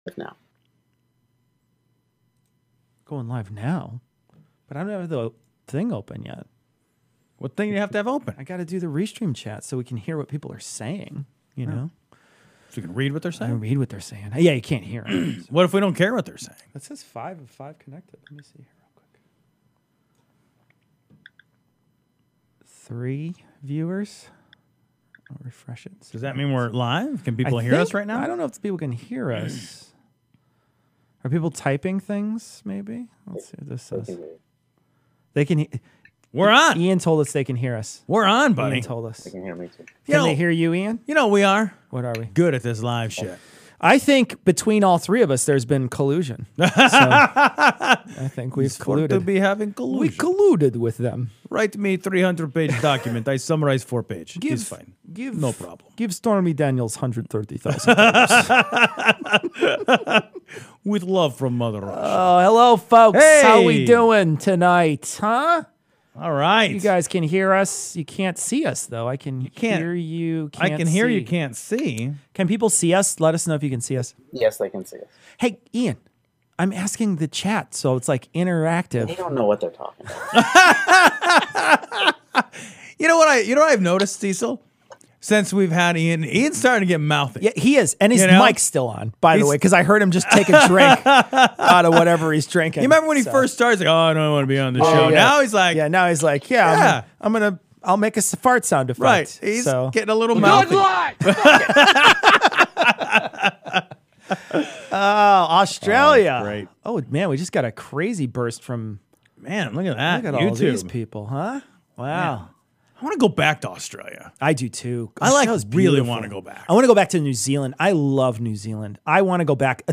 Livestream from Thursday the 28th